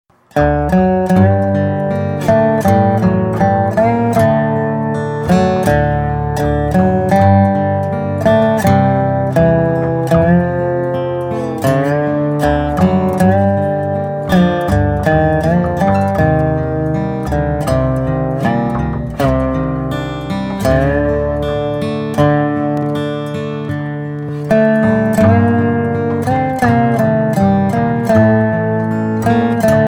An album of Swedish Bluegrass music.